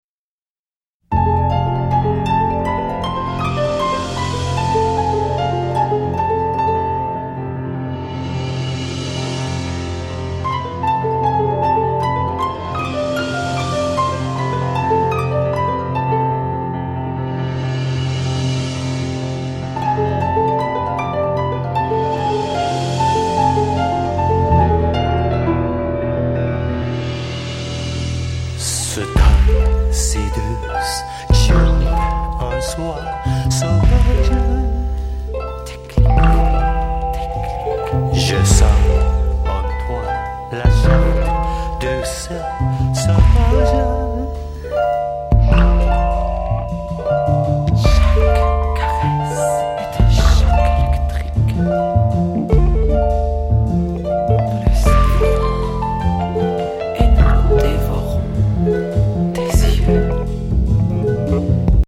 Stylisher Avantgarde-Bossa Nova